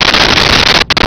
Sfx Crash Firey A
sfx_crash_firey_a.wav